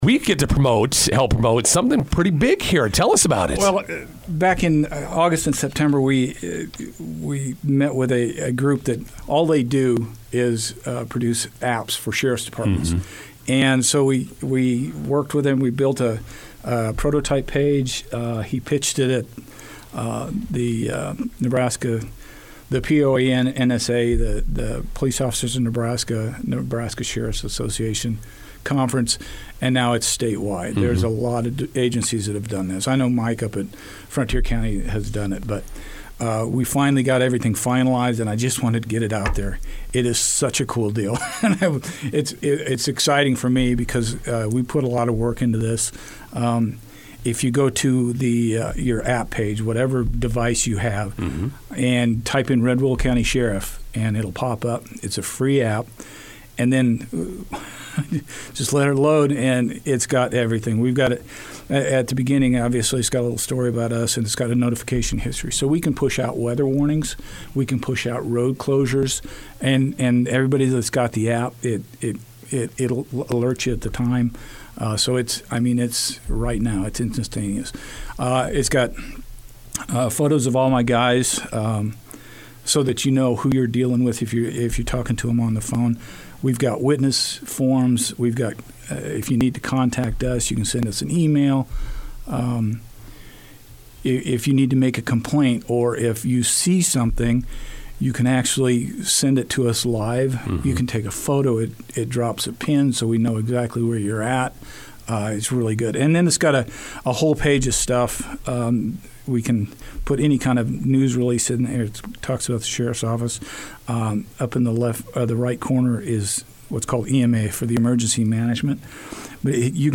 INTERVIEW: Red Willow County Sheriff’s Department debuts new app.